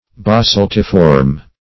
Search Result for " basaltiform" : The Collaborative International Dictionary of English v.0.48: Basaltiform \Ba*salt"i*form\, a. [Basalt + -form.] In the form of basalt; columnar.